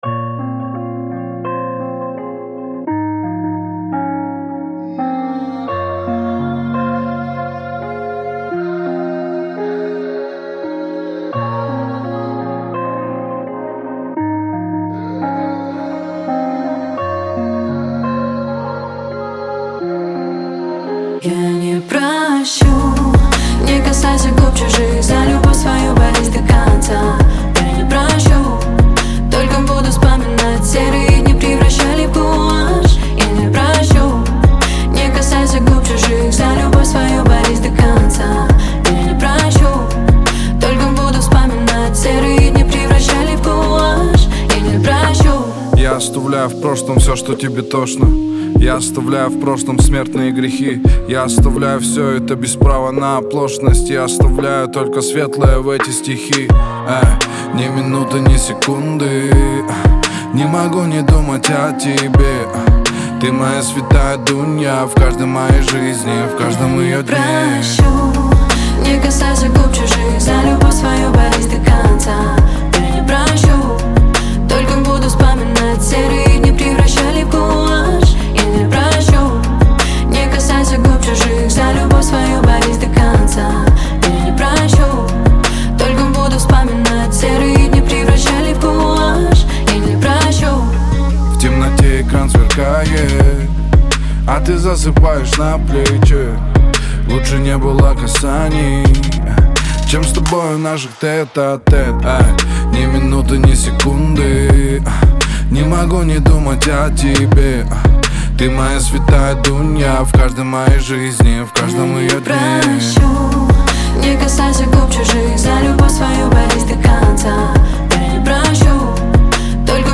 песня в жанре хип-хоп и R&B